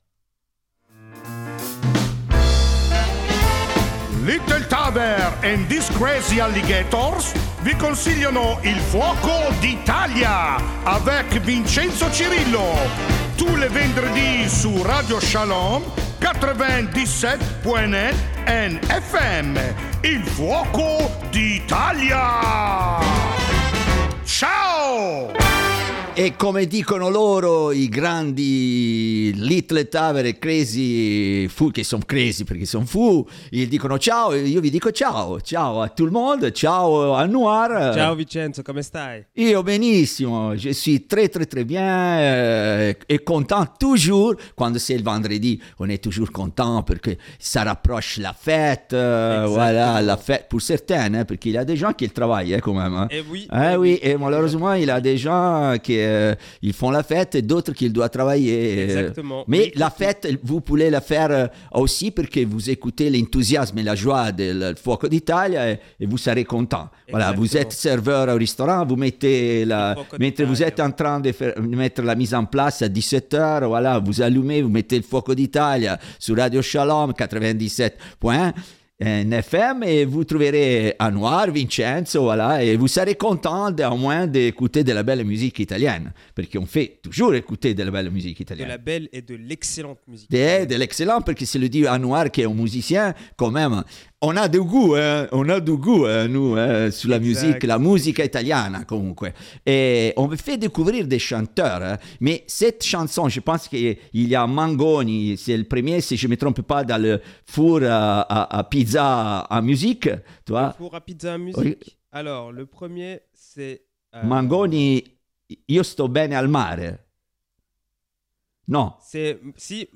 31 octobre 2025 Écouter le podcast Télécharger le podcast Dans ce nouvel épisode, plongez dans l’écoute de la musique italienne. Classiques ou contemporains, connus ou rares, les morceaux sont proposés pour le plaisir de l’écoute.